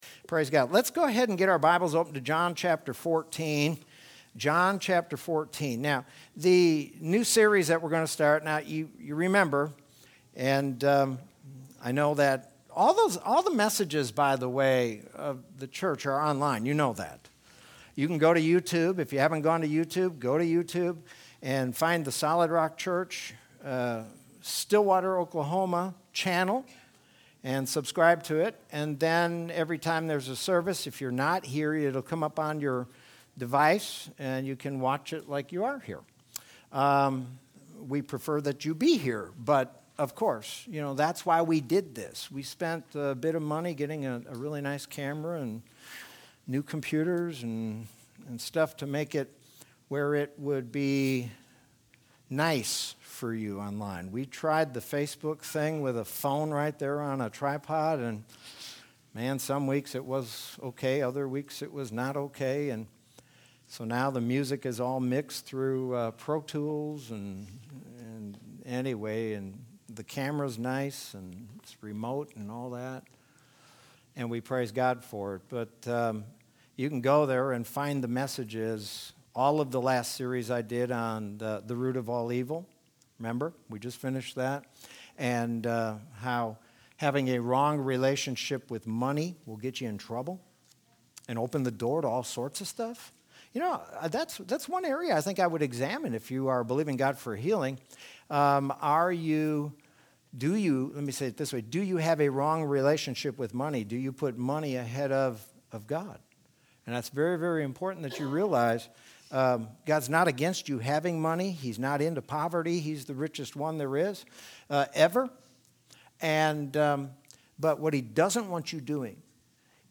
Sermon from Sunday, February 21st, 2021.